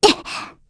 Estelle-Vox_Jump_kr.wav